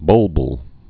(bŭlbəl, -bĭl)